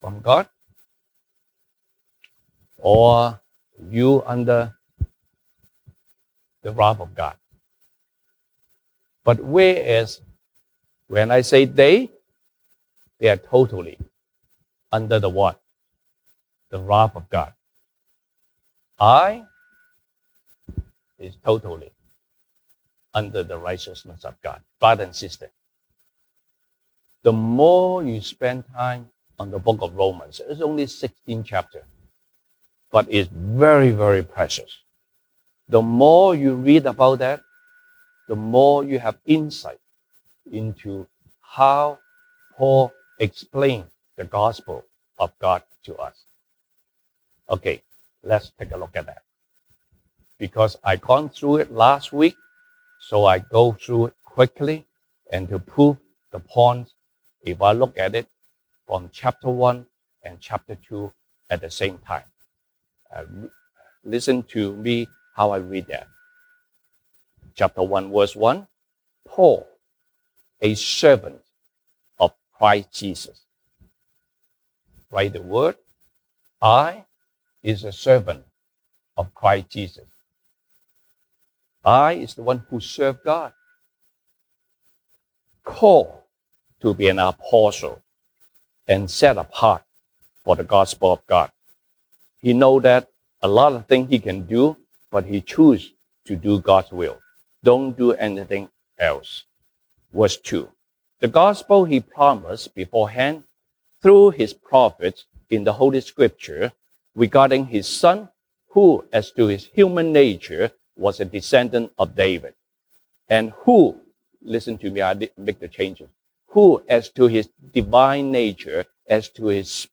Sunday Service English Topics: God's Righteousness vs God's wrath